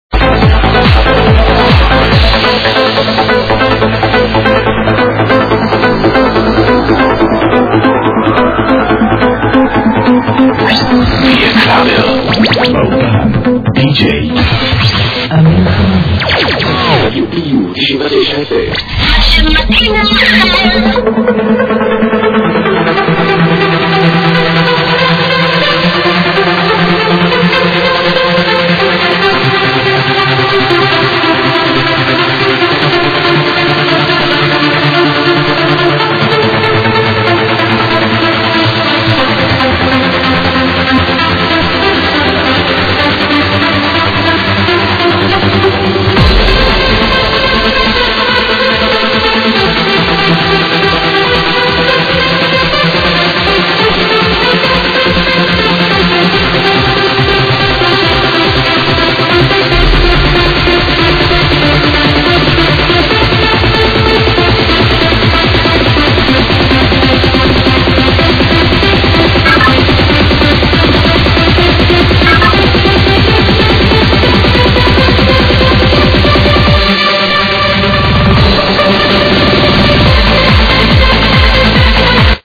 Trance tuneage ID?